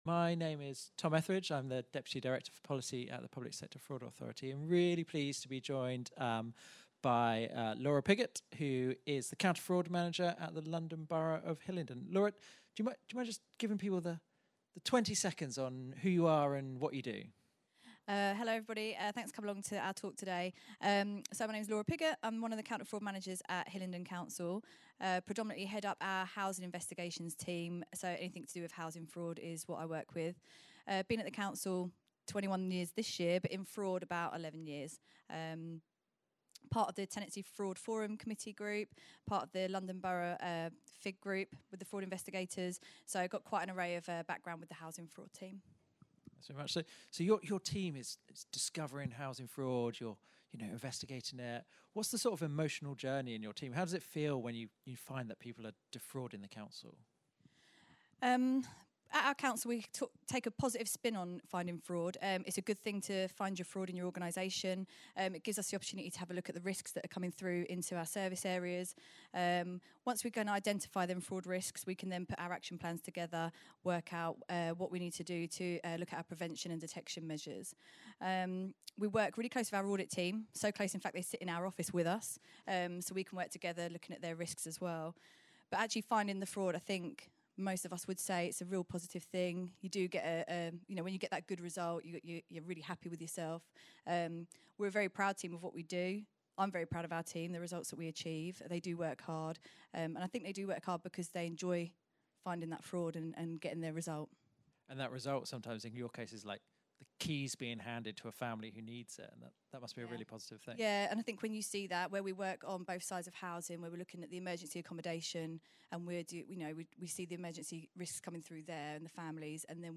LIVE DATE: 26th February 2026, at the Counter Fraud conference, QEII Centre, London.